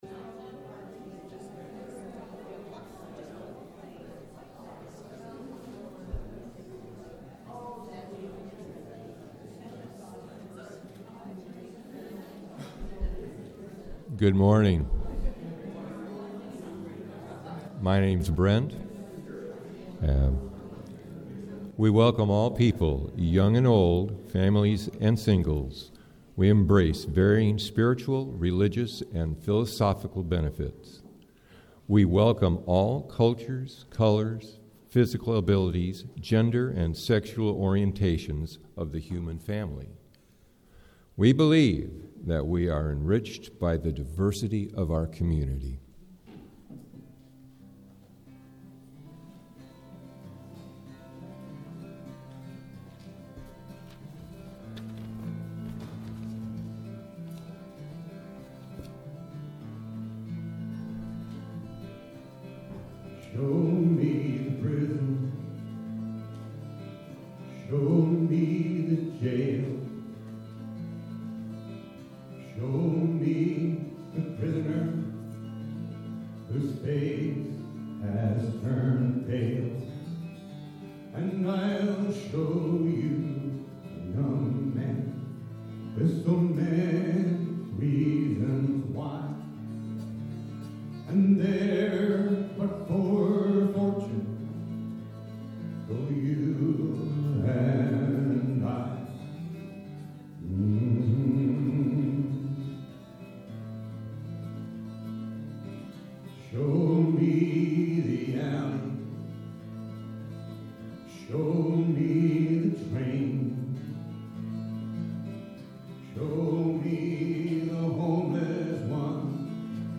In this program, the folk singer returns to perform songs that give musical voice to this essential Principle of UU values.